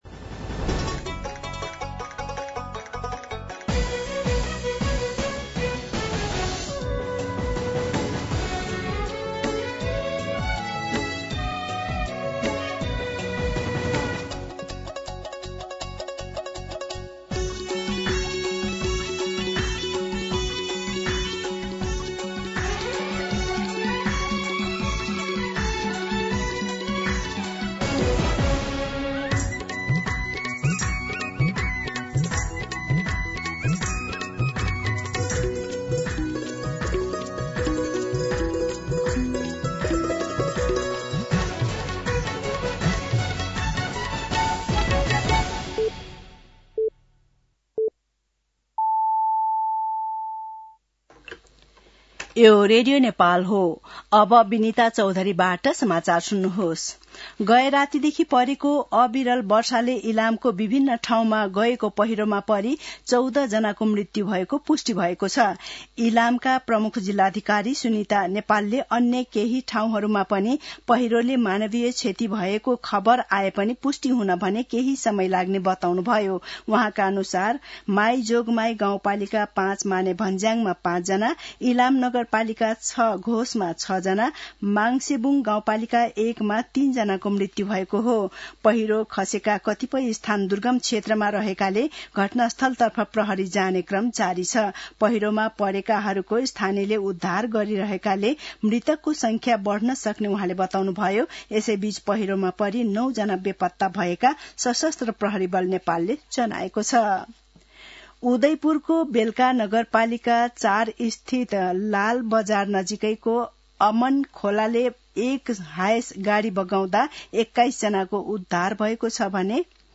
दिउँसो १ बजेको नेपाली समाचार : १५ असोज , २०८२